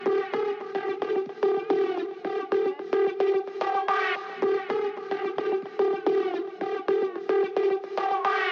slemdung-siren.mp3